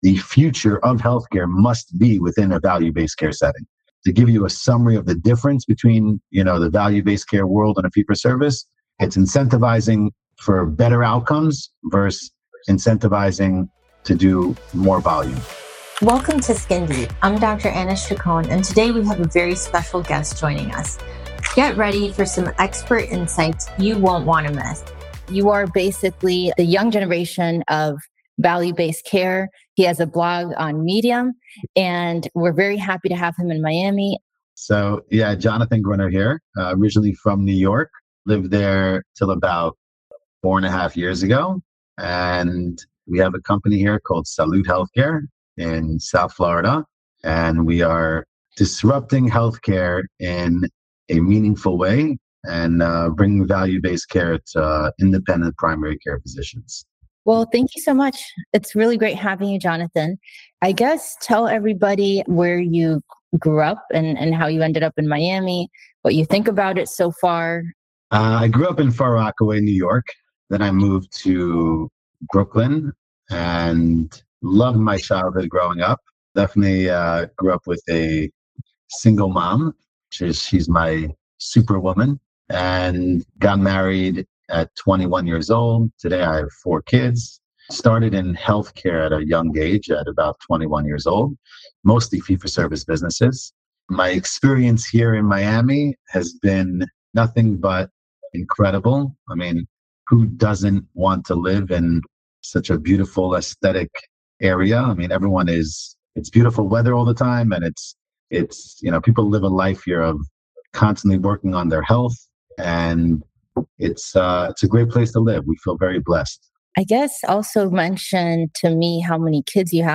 The conversation explores Medicare Advantage mechanics, capitation rates, and risk-sharing arrangements.